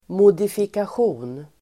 Ladda ner uttalet
Uttal: [modifikasj'o:n]